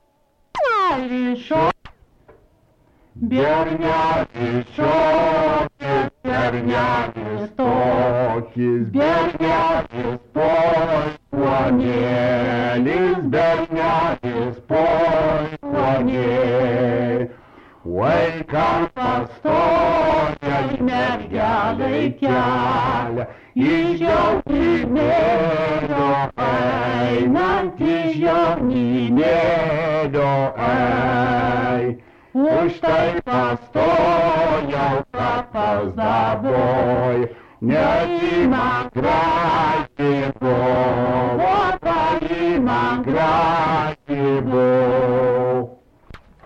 Dalykas, tema daina
Erdvinė aprėptis Dargužiai Viečiūnai
Atlikimo pubūdis vokalinis